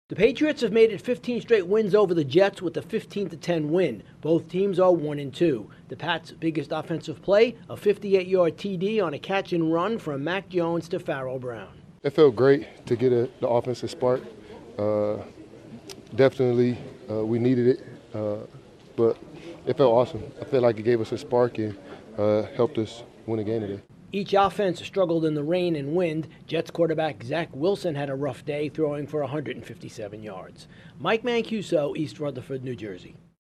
It's the same old story for the Patriots against the Jets. Correspondent